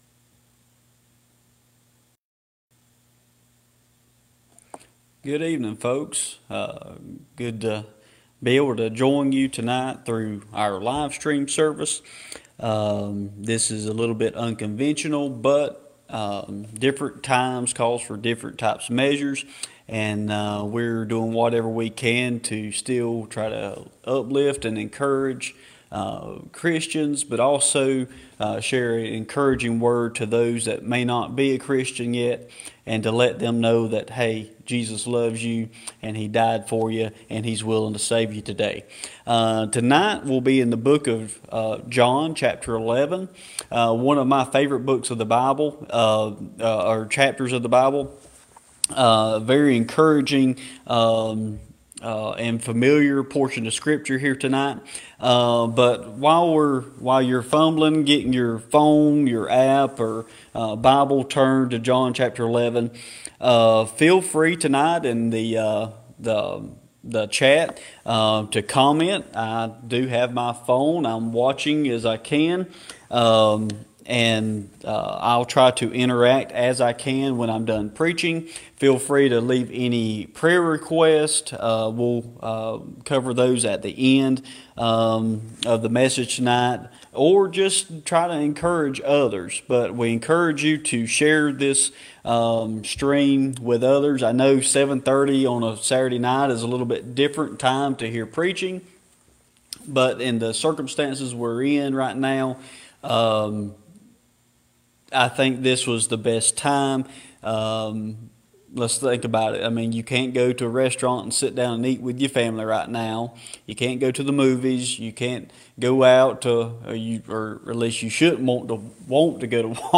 Sermons | Silver Leaf Baptist Church